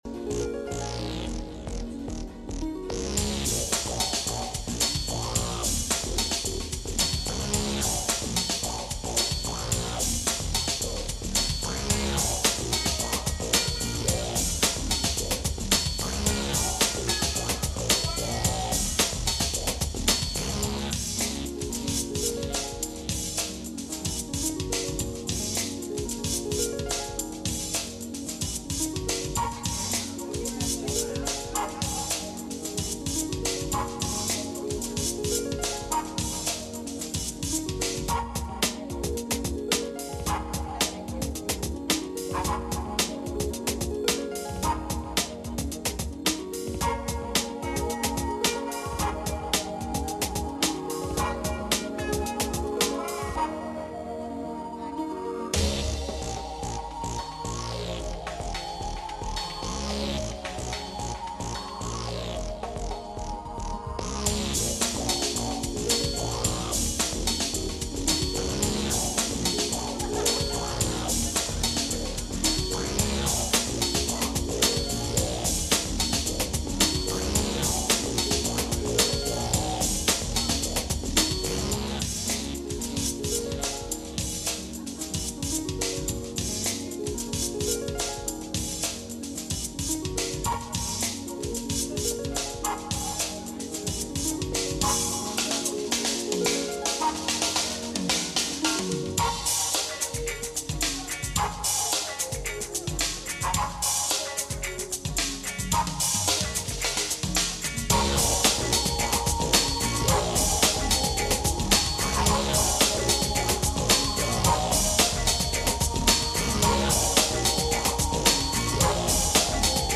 Matthew 27:46 Service Type: Midweek Meeting « A Virtuous Woman